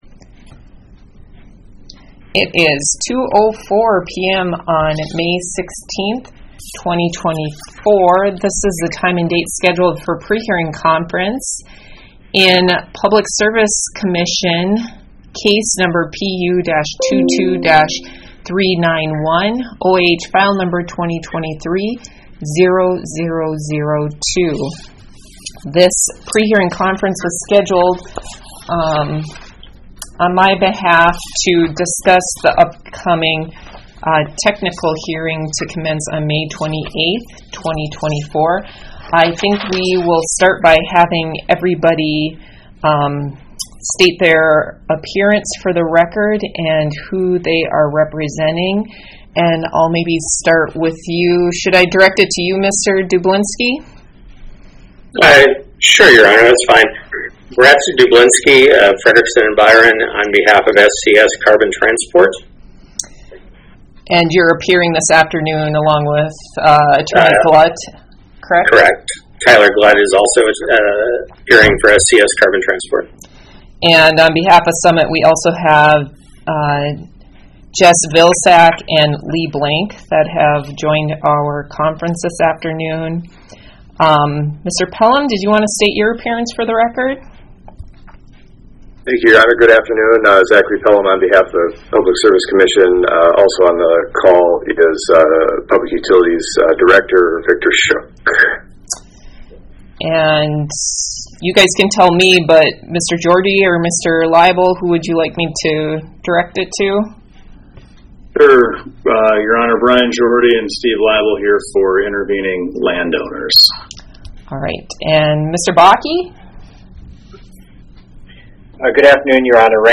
Electronic Recording of 16 May 2024 Prehearing Conference